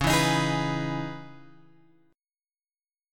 C# Minor 9th